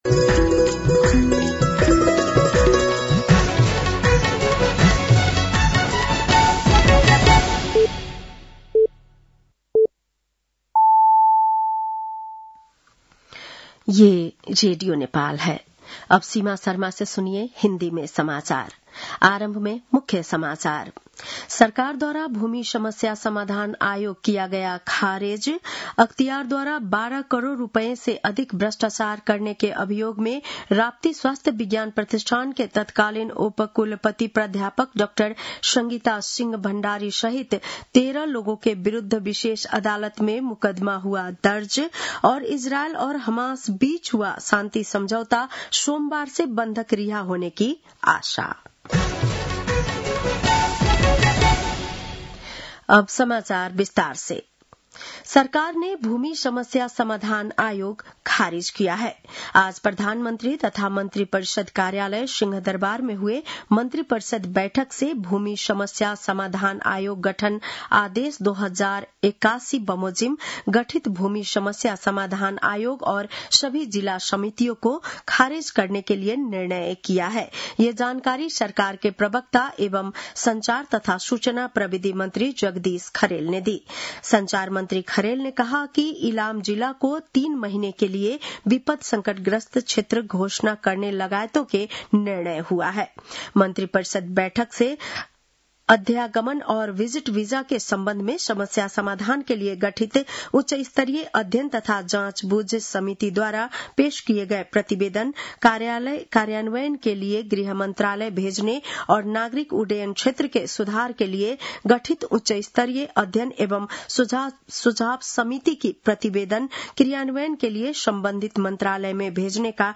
बेलुकी १० बजेको हिन्दी समाचार : २३ असोज , २०८२
10-PM-Hindi-NEWS-.mp3